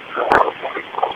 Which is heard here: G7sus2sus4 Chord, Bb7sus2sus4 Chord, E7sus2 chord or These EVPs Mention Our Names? These EVPs Mention Our Names